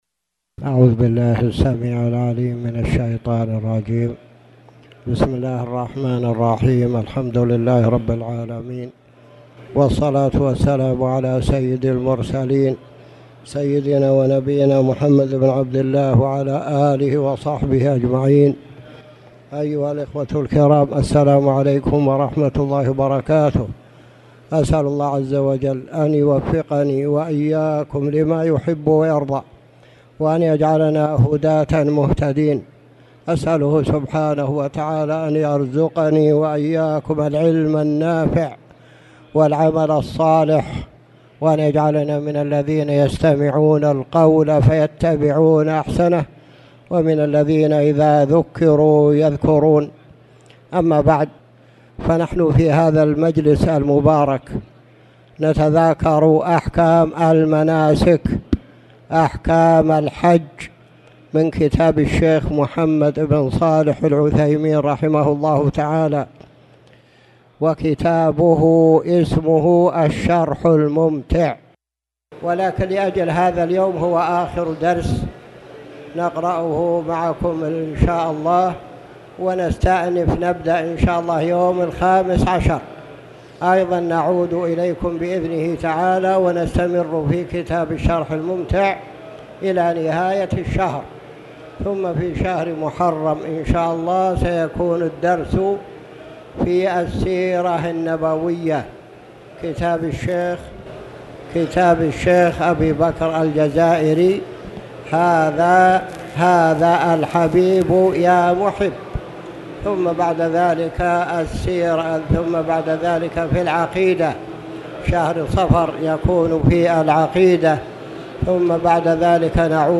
تاريخ النشر ٣٠ ذو القعدة ١٤٣٨ هـ المكان: المسجد الحرام الشيخ